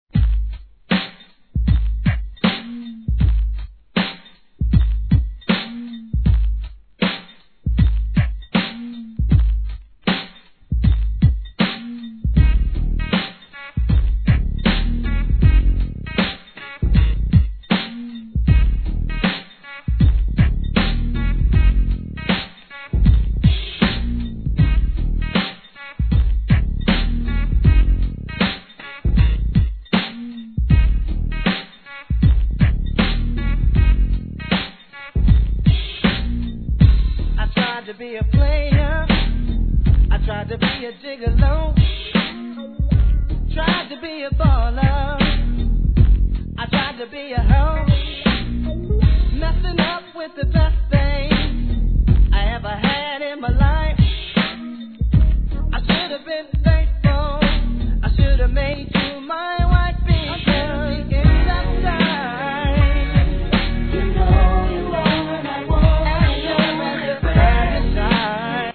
HIP HOP/R&B
1996年、最高のコーラスで王道を行く好R&B!